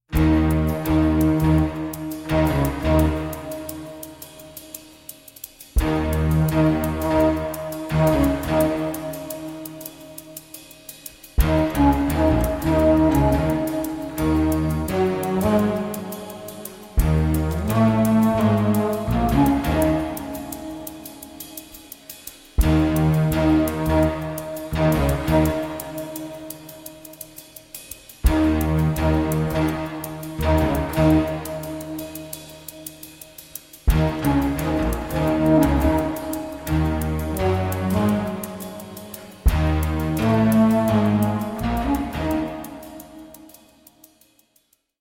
Spannung, Romantik und große Melodien.